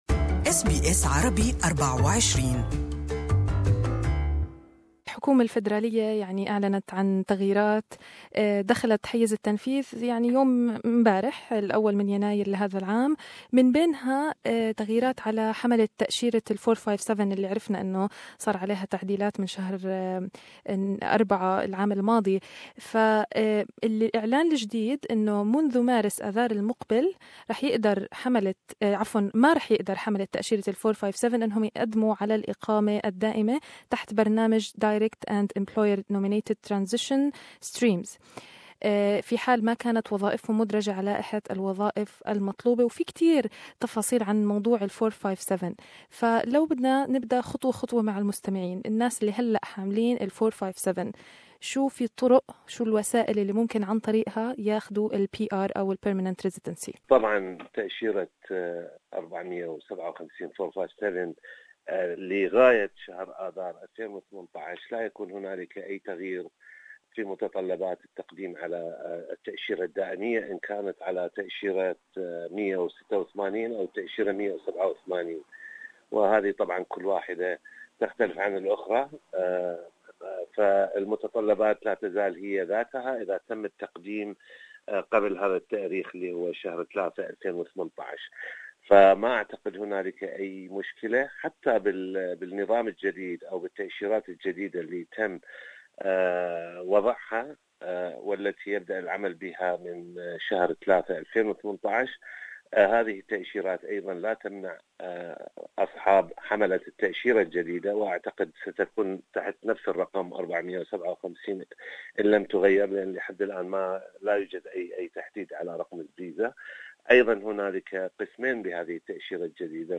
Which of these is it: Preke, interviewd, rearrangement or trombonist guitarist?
interviewd